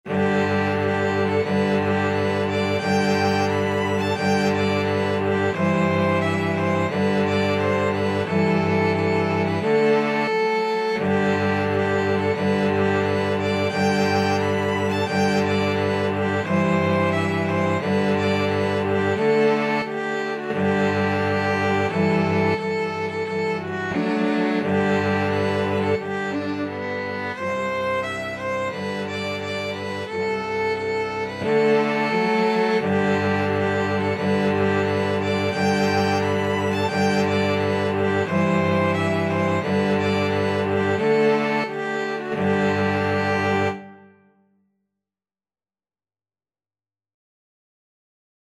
G major (Sounding Pitch) (View more G major Music for Violin-Cello Duet )
4/4 (View more 4/4 Music)
Steadily =c.88
Violin-Cello Duet  (View more Easy Violin-Cello Duet Music)
Traditional (View more Traditional Violin-Cello Duet Music)